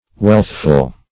Search Result for " wealthful" : The Collaborative International Dictionary of English v.0.48: Wealthful \Wealth"ful\, a. Full of wealth; wealthy; prosperous.